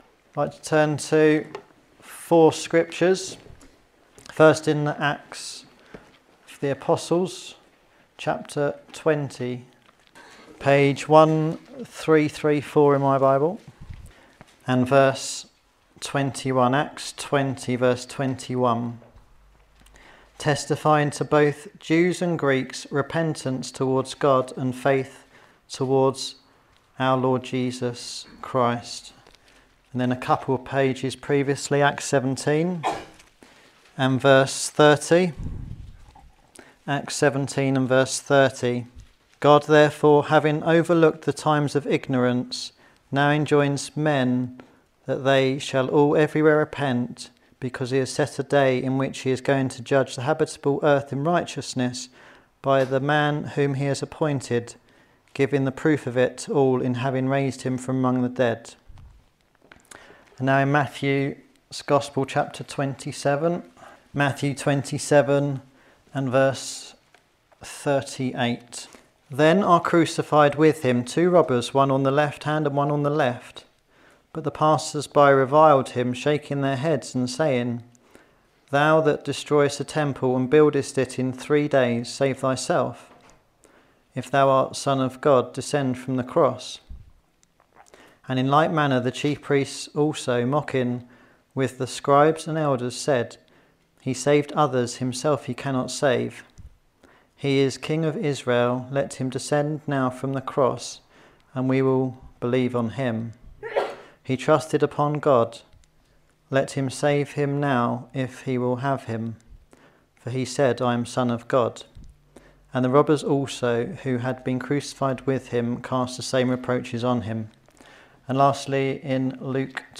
Twitter YouTube Facebook Instagram TikTok Discover over 2000 recordings of Gospel preaching & Bible teaching now online.